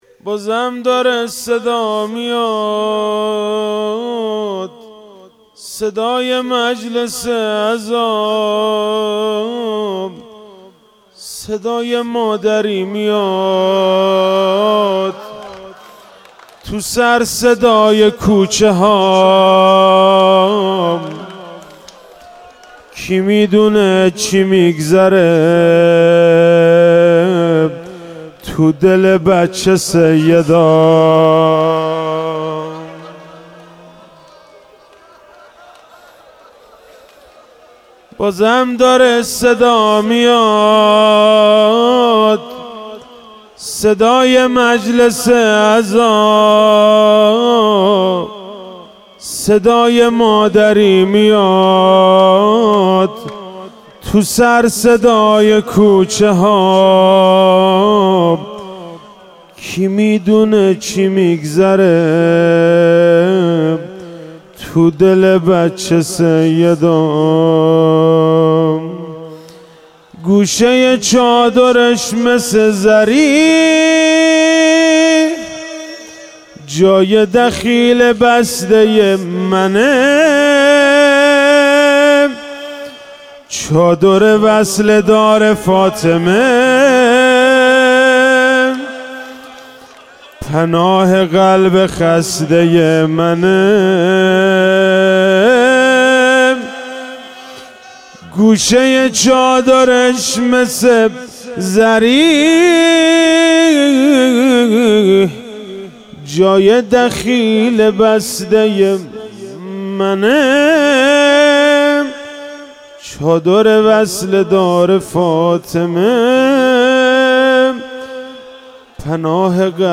خیمه حضرت فاطمه زهرا سلام الله علیها